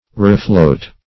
Search Result for " refloat" : Wordnet 3.0 VERB (1) 1. set afloat again ; - Example: "refloat a grounded boat" The Collaborative International Dictionary of English v.0.48: Refloat \Re"float\ (r?"fl?t), n. Reflux; ebb.